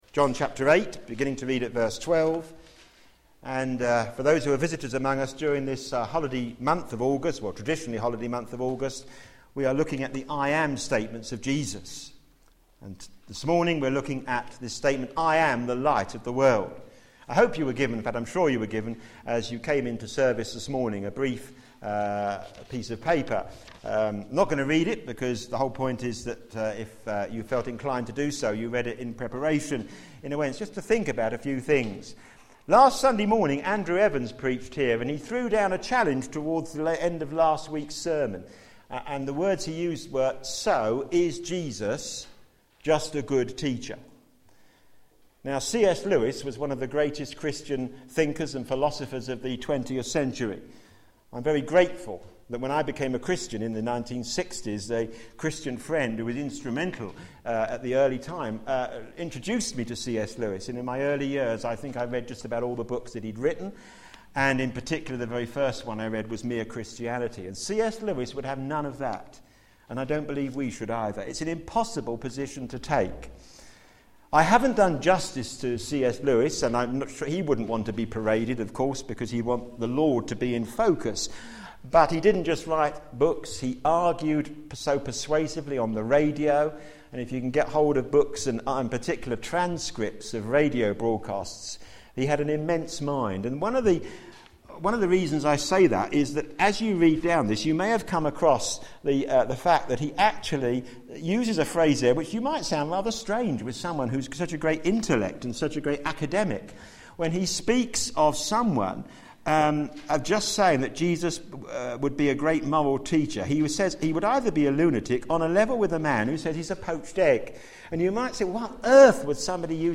Media Library Media for a.m. Service on Sun 07th Aug 2011 10:30 Speaker
Theme: Jesus Said - I Am the Light of the World Sermon In the search box below, you can search for recordings of past sermons.